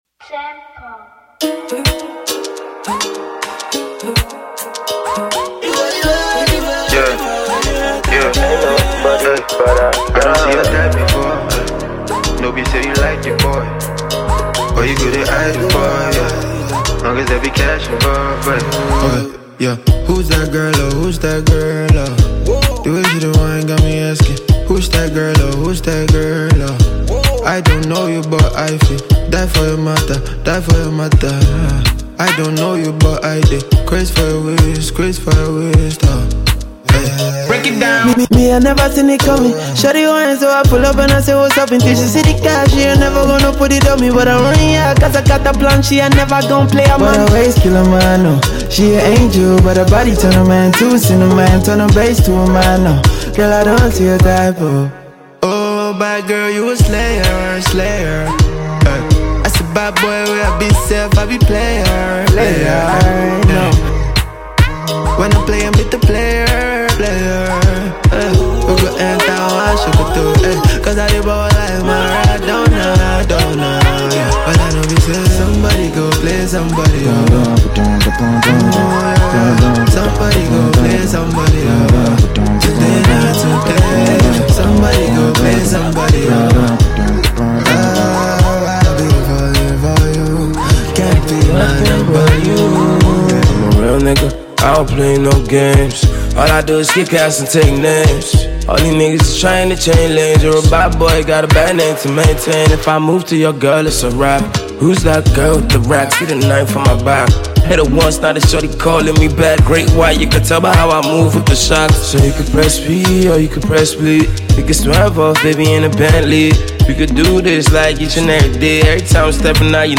Budding Nigerian rapper